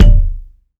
Kick (26).wav